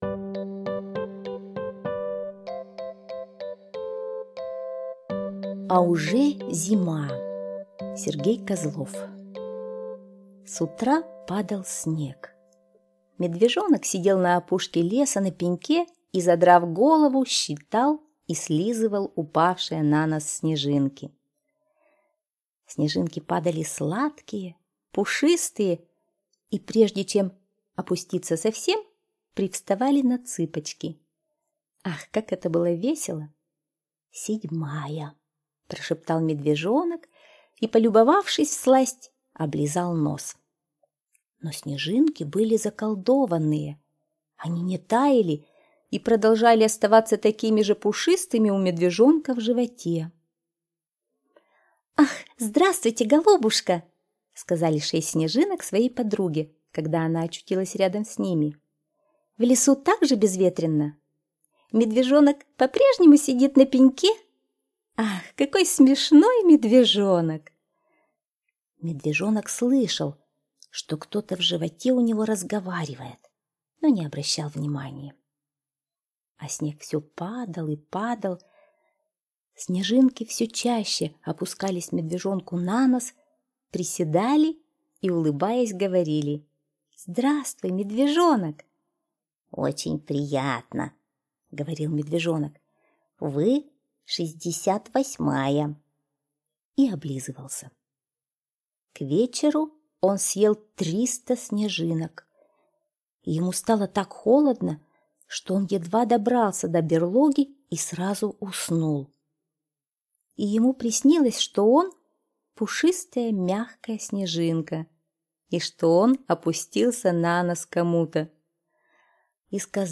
А уже зима – Козлов С.Г. (аудиоверсия)
Аудиокнига в разделах